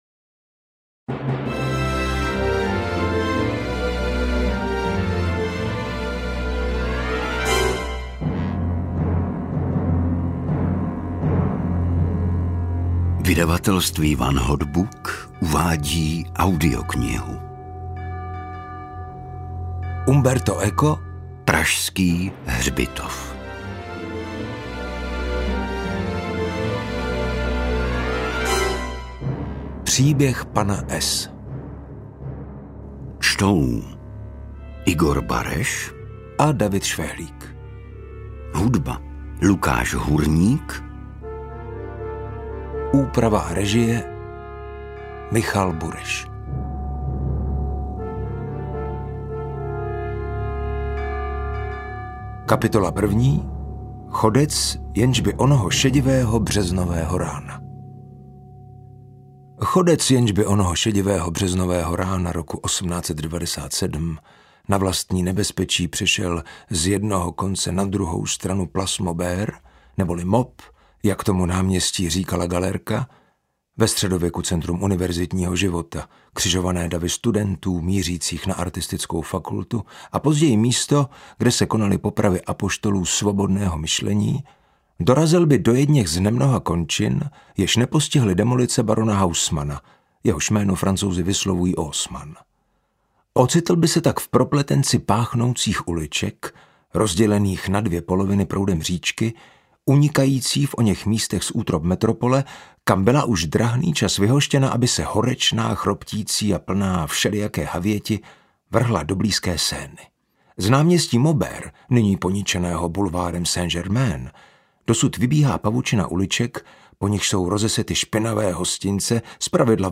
Interpreti:  Igor Bareš, David Švehlík